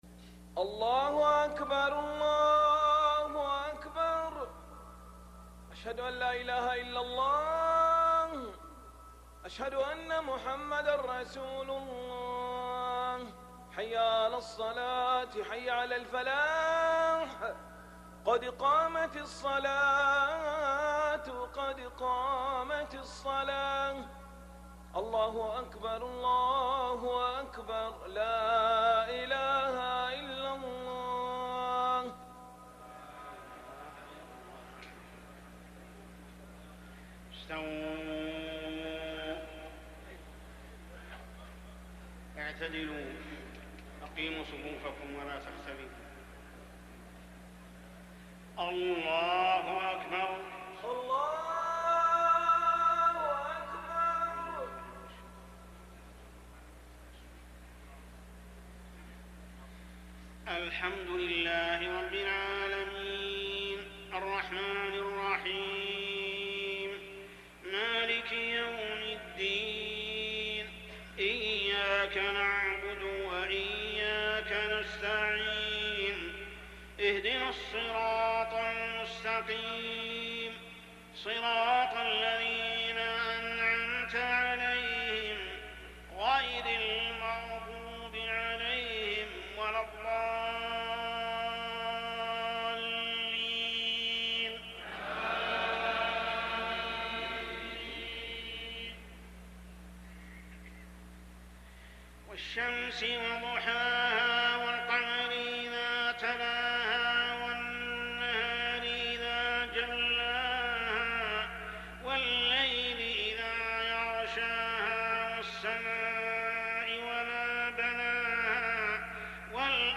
سورتي الشمس والزلزلة للشيخ محمد السبيل رحمه الله > 1420 🕋 > الفروض - تلاوات الحرمين